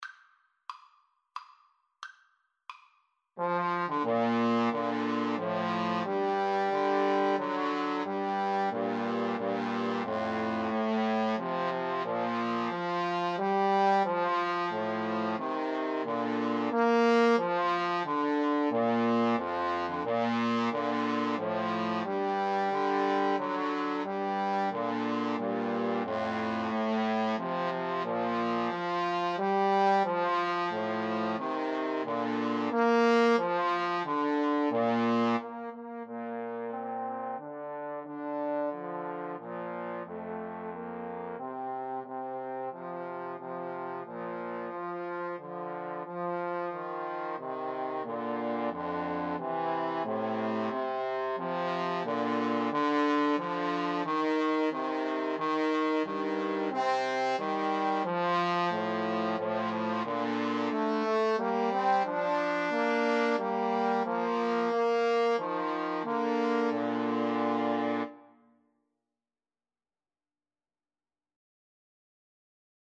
3/4 (View more 3/4 Music)
Bb major (Sounding Pitch) (View more Bb major Music for Trombone Trio )
Maestoso = c.90
Trombone Trio  (View more Intermediate Trombone Trio Music)